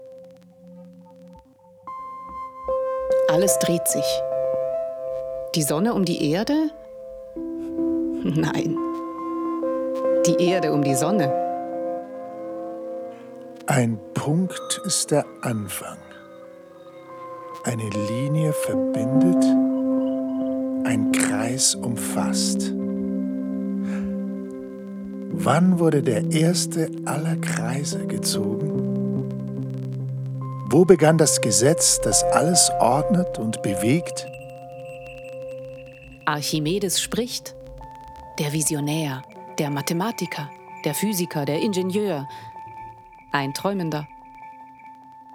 Ein Audiowalk durch die Nagelfabrik Winterthur
Auf dem Audiowalk wird das Publikum von einer Stimme im Ohr durch die atmosphärischen Hallen begleitet: Fabrikarbeiter:innen machen sich ans Werk und melden sich lautstark zu Wort. Eiserne Titanen geben den Takt an.